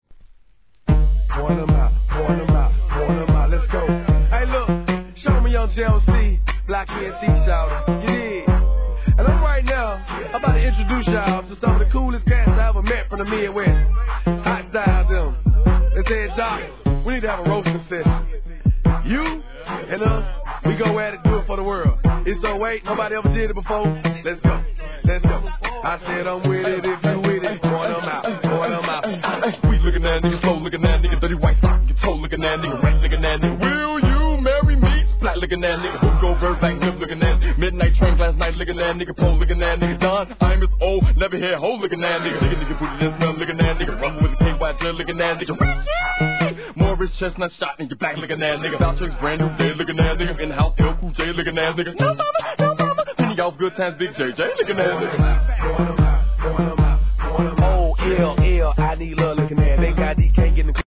HIP HOP/R&B
ファミコン音ぽいチープな中毒性ダウンナー!